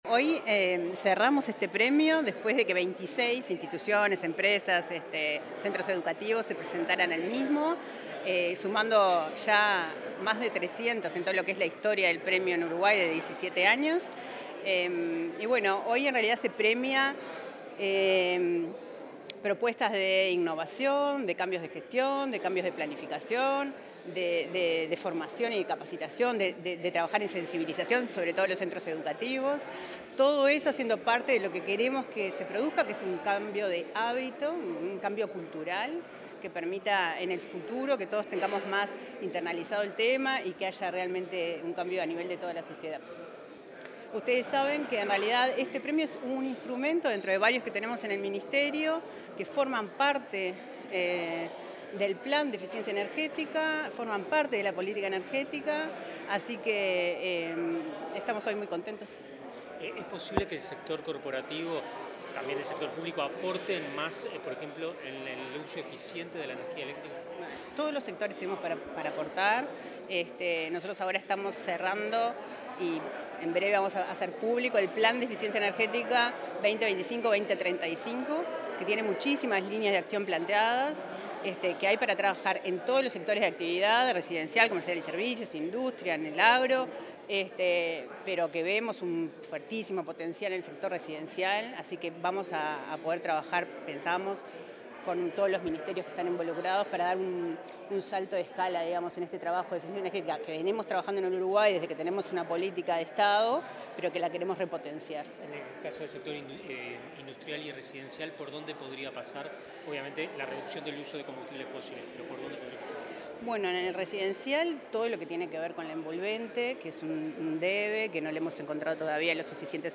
Declaraciones de la directora nacional de Energía, Arianna Spinelli
En el marco de la ceremonia de Entrega del Premio Nacional de Eficiencia Energética, Arianna Spinelli realizó declaraciones a la prensa.